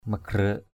/mə-ɡ͡ɣrə:˨˩ʔ/